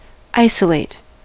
isolate: Wikimedia Commons US English Pronunciations
En-us-isolate.WAV